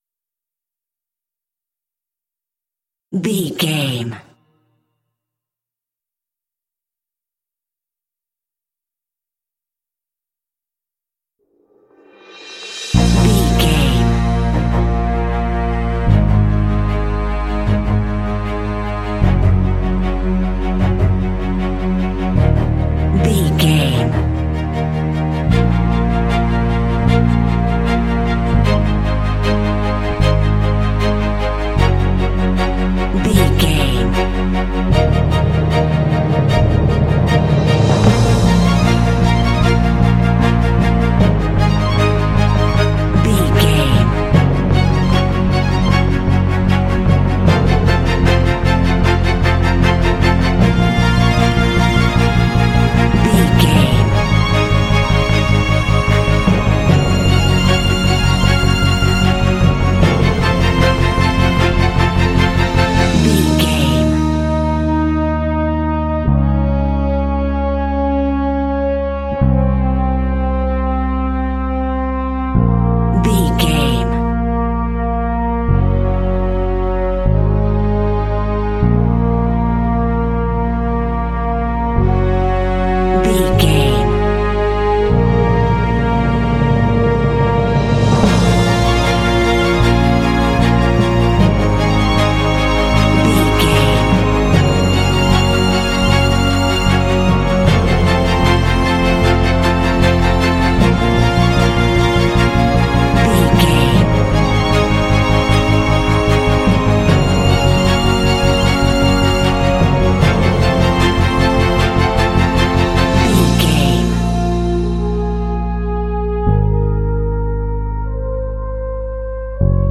Epic / Action
Fast paced
Aeolian/Minor
B♭
strings
brass
orchestra
cinematic
underscore